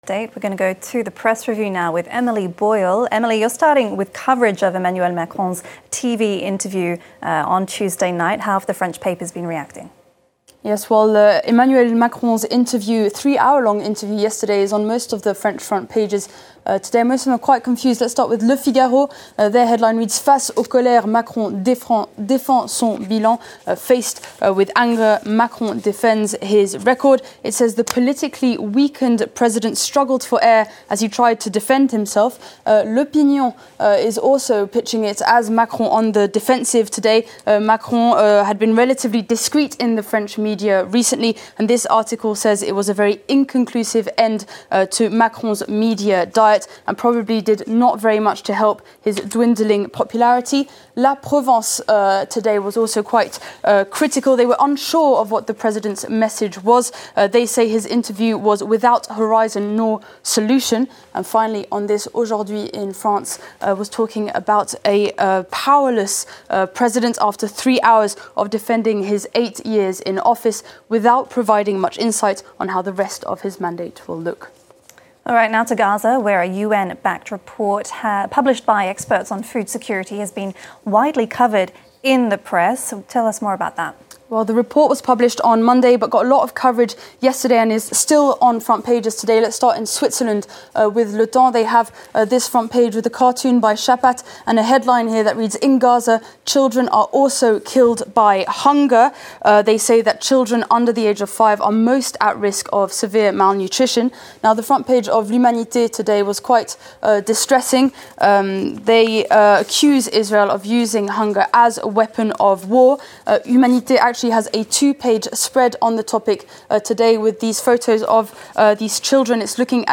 PRESS REVIEW – Wednesday, May 14: French papers are slightly unsure of what to make of President Emmanuel Macron's three-hour interview on national television. Also, a recent report on the risk of famine in Gaza continues to make front pages, as Israel's aid blockade continues.